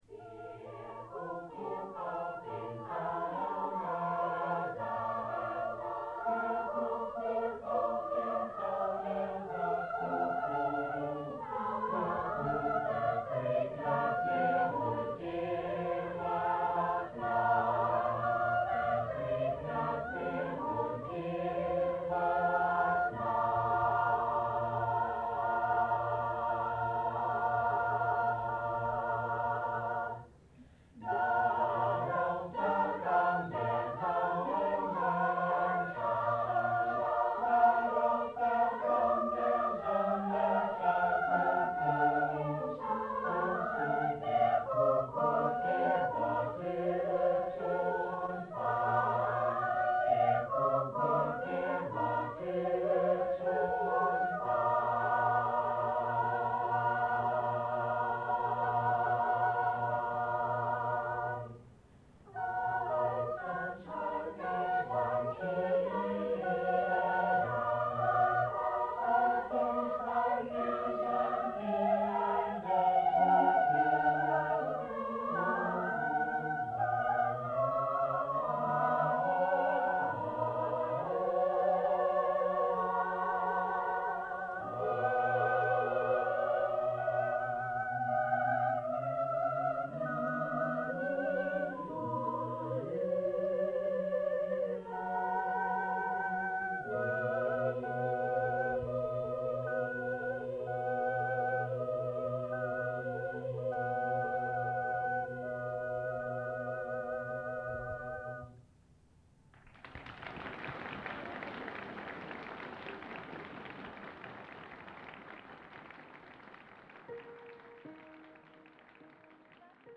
Collection: Bückeburg Garden Party
Genre: | Type: Director intros, emceeing